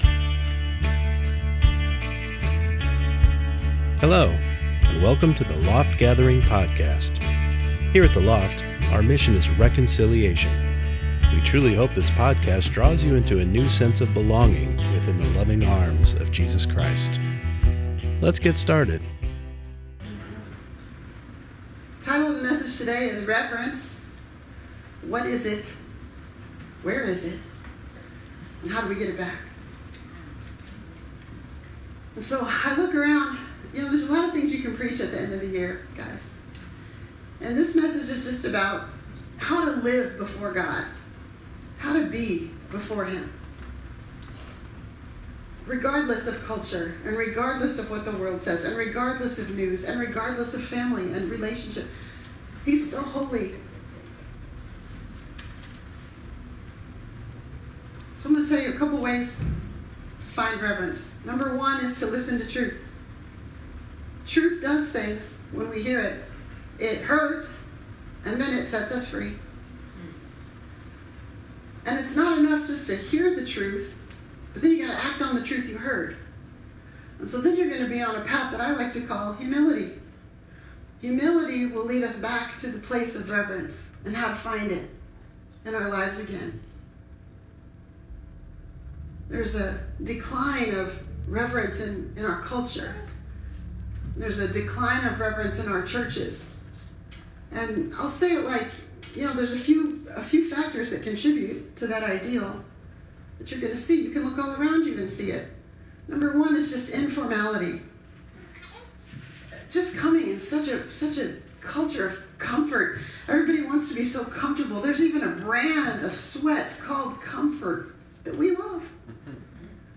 Service: Sunday Morning Service